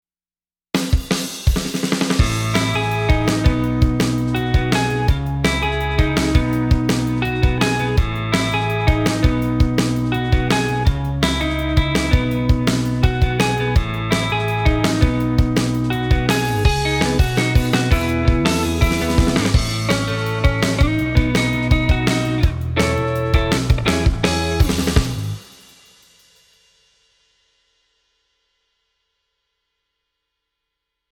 クリーンサウンドでも同様です。
軽快なポップスでよくあるアルペジオ主体のクリーンサウンドを、JJP Guitars で「音作り」しています。キラキラっとした質感がより強調されながら、粒立ちの良いサウンドになってますね。
タイプに「CLEAN」を選び、粒立ちを揃えるためにメインセクションでやや深めにコンプをかけています。
ただし、それだけだと抑揚にやや欠けてしまったため、「ATTACK」スライダーを上げてピッキングした時のキラッとした質感を少しだけ強調しています。